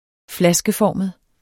Udtale [ -ˌfɒˀməð ]